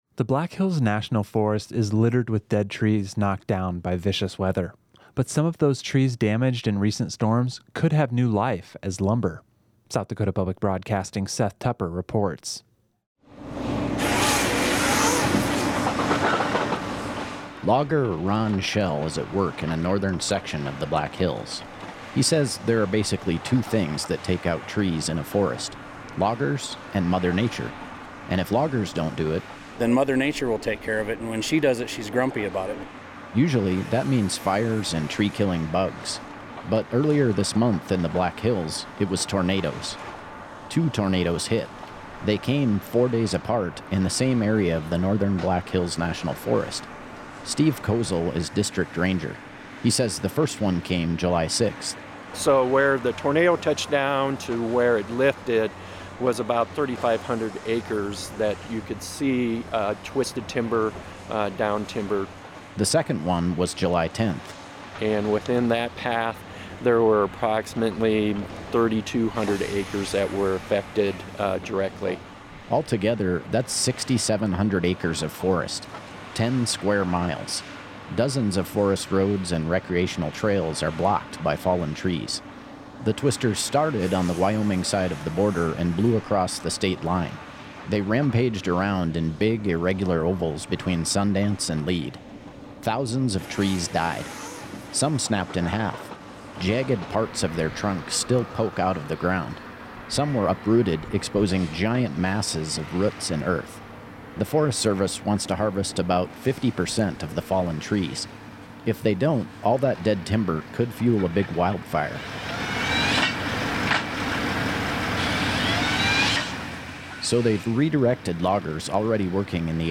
The site is busy and loud. Operators run big pieces of equipment.
news